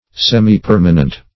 Semipermanent \Sem`i*per"ma*nent\, n.